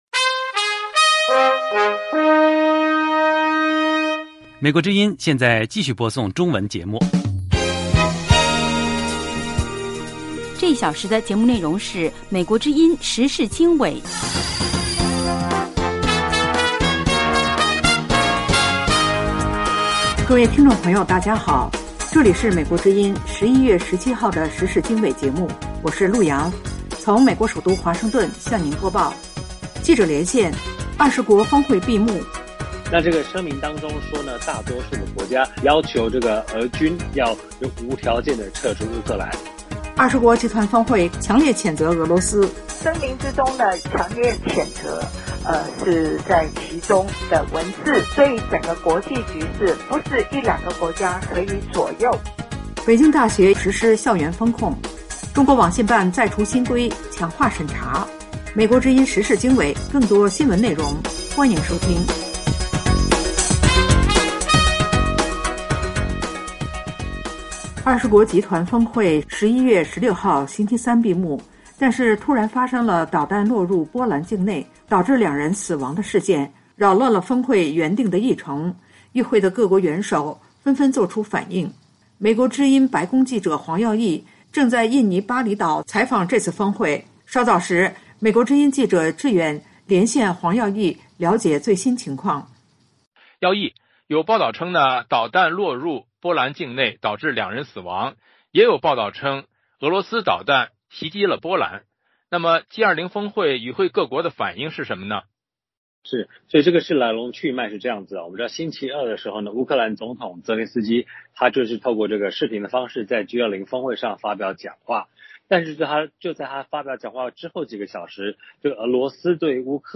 时事经纬(2022年11月17日) - 记者连线：G20闭幕没有公报只有声明;20国集团峰会共同强烈谴责俄罗斯